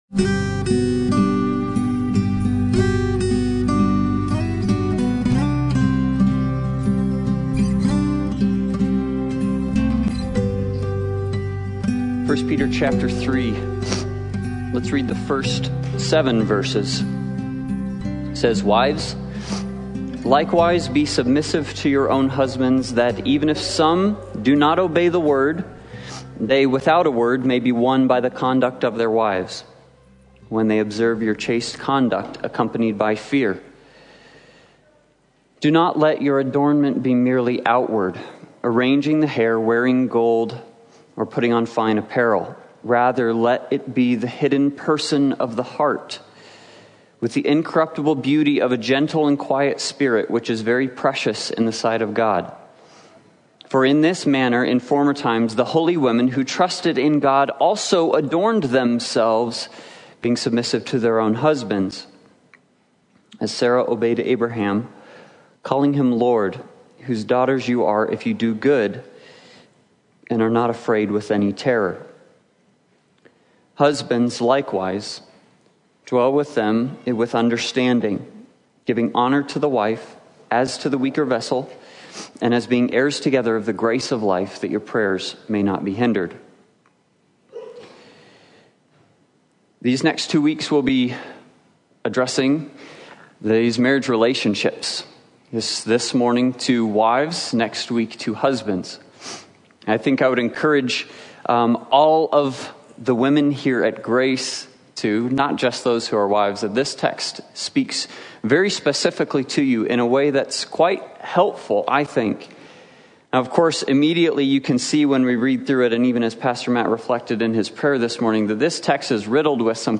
1 Peter3:1-6 Service Type: Sunday Morning Worship « He Bore Our Sins